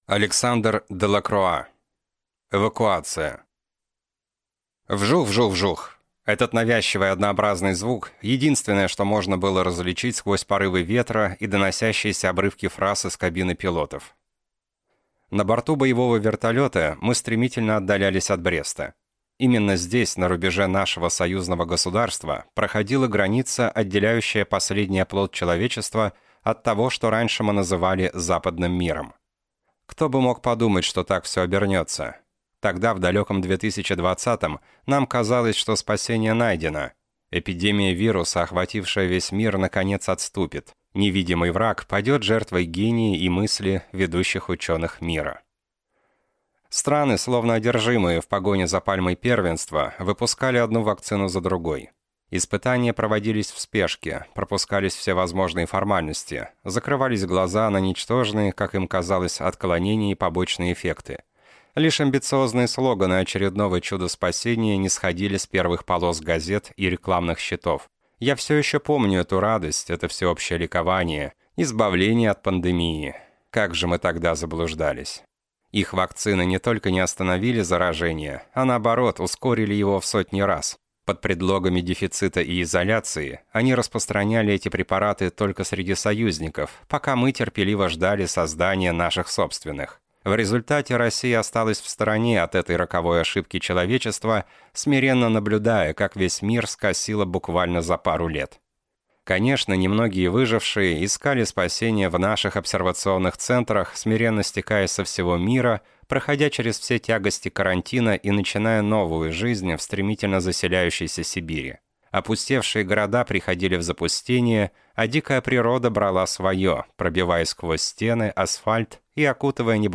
Аудиокнига Эвакуация | Библиотека аудиокниг